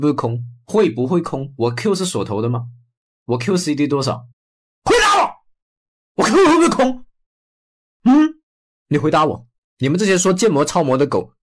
AI-röstöverlägg för korta matlagningsvideor
Fånga uppmärksamhet omedelbart med slagkraftig, snabb AI-berättarröst designad för kulinariskt innehåll på TikTok, Instagram Reels och YouTube Shorts.
Text-till-tal
Viral tempo
Energisk ton
Det levererar koncis, högenergetisk berättarröst som fångar tittare inom de första tre sekunderna. Optimerad för vertikala videoformat som Reels och Shorts, skär denna AI-röst genom bruset med tydlig artikulation och trendig intonation.